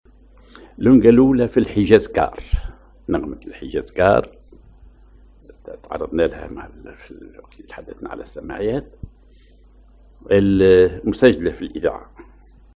Maqam ar الحجاز كار
سجلتها الإذاعة الوطنية التونسية genre لونڤة